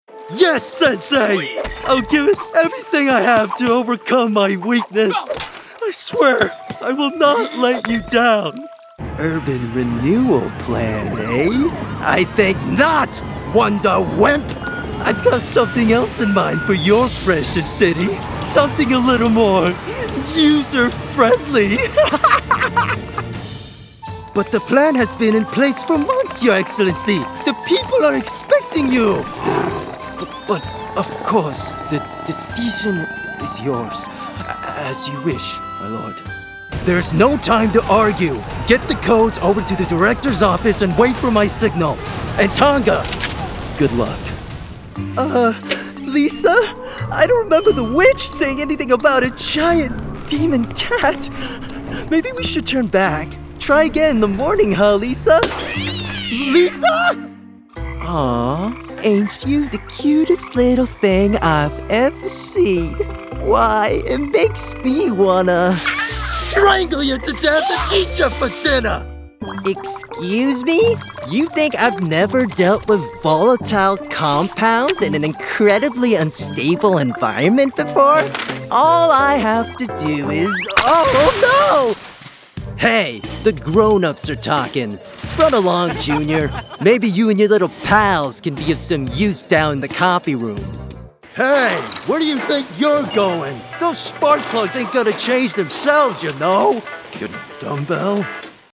Voice Over Talent
Animation Demo
Laidback with a richness that adds a little something extra.
Warm, engaging, and approachable with a professional turnaround and top-quality audio.